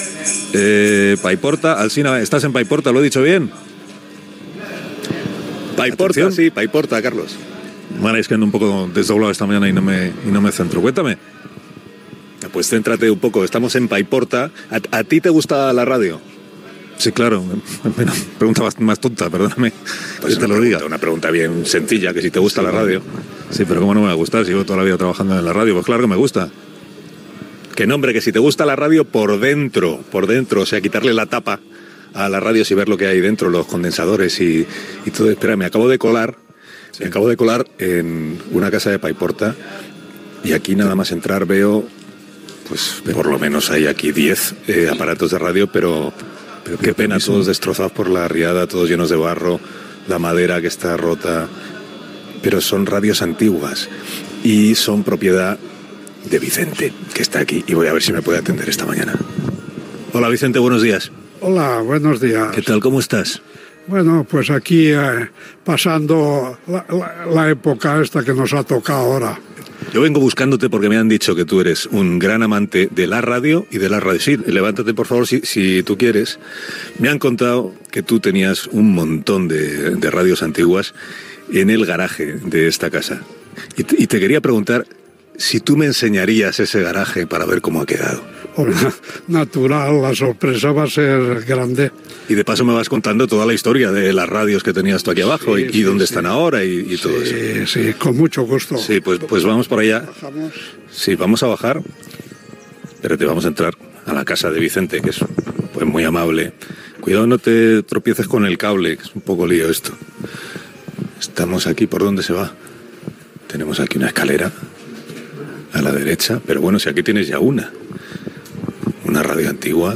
Gènere radiofònic Entreteniment Presentador/a Alsina, Carlos